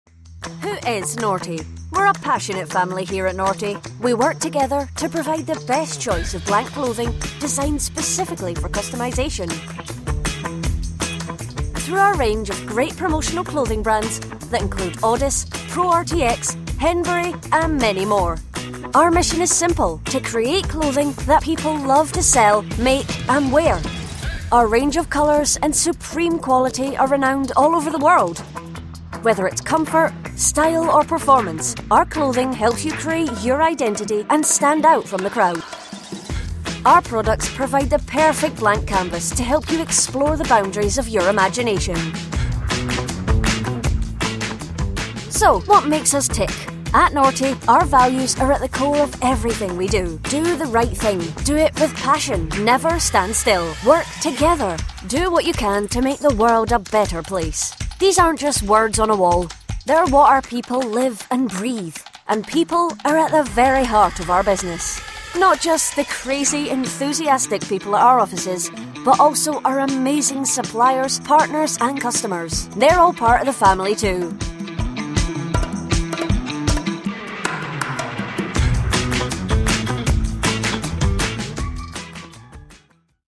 Inglês (escocês)
Vídeos Corporativos